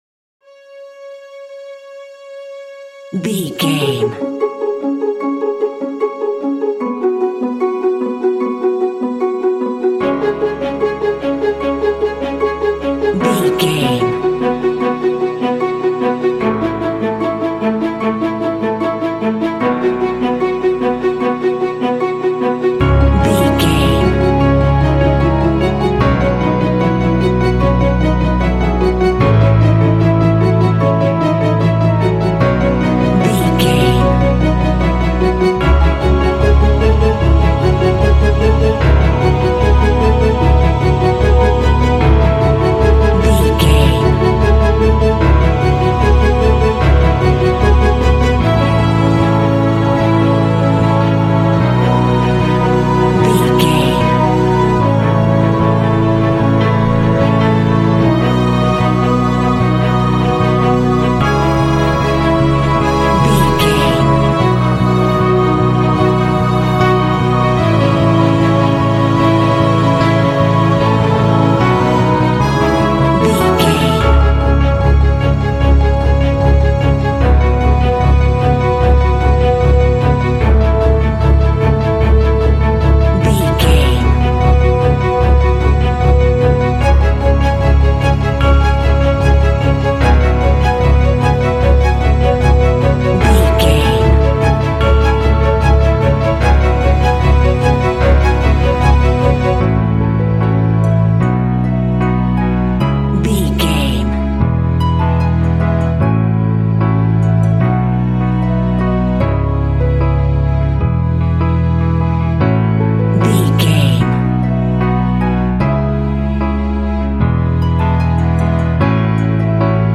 Aeolian/Minor
C#
motivational
driving
dramatic
strings
piano
harp
brass
cinematic
symphonic rock